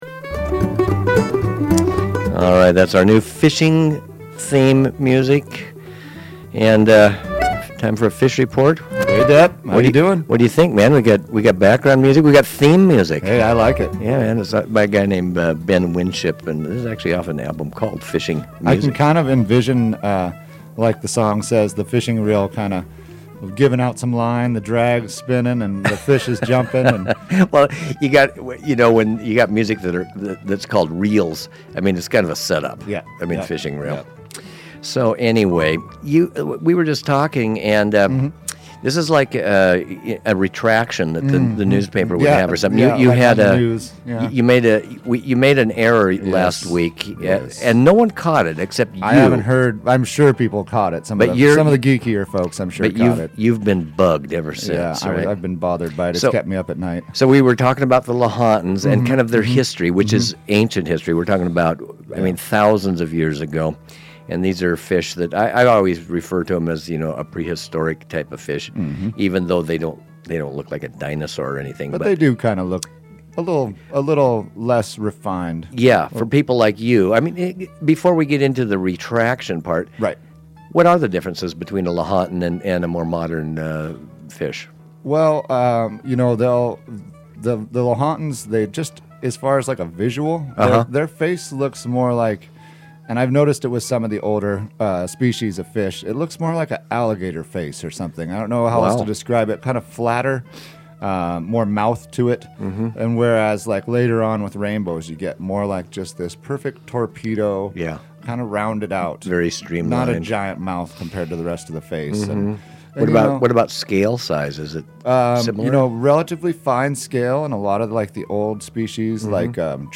KTRT Methow Fishing Report